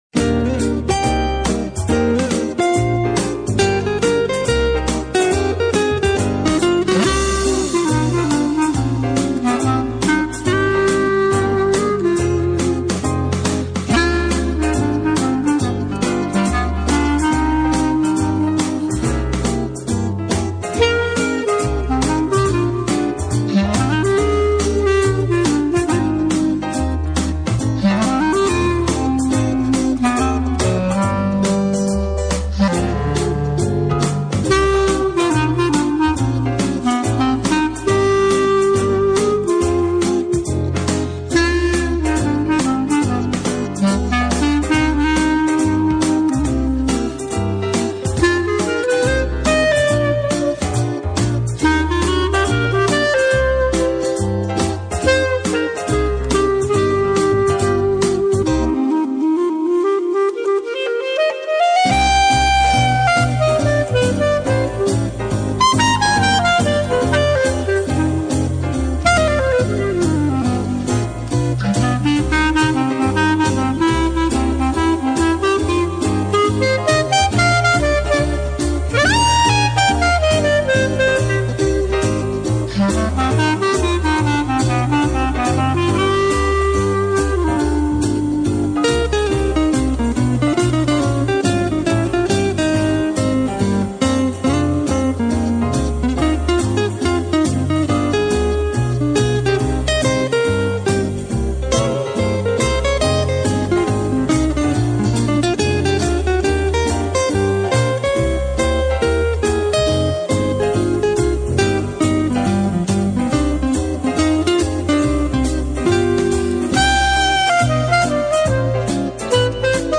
Clarinetto in sib.
chitarra.